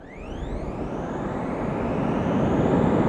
autopilotstart.wav